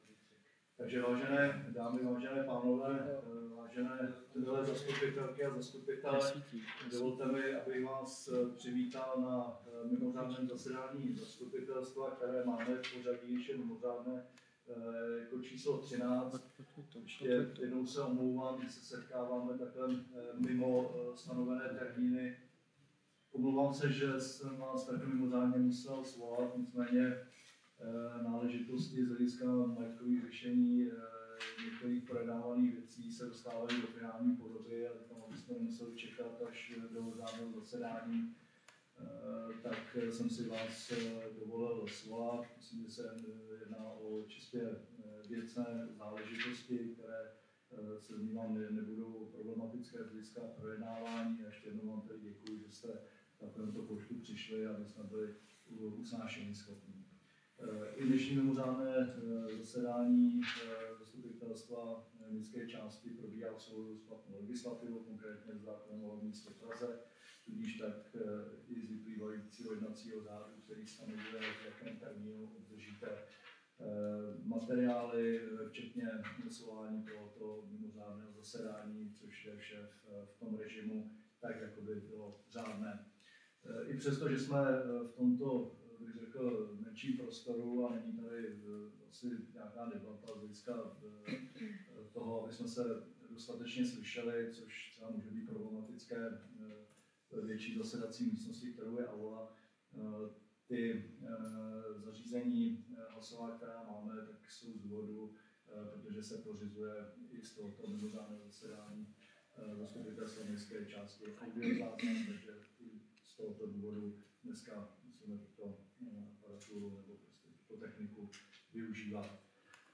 Audiozáznamy z jednání zastupitelstva 2022-2026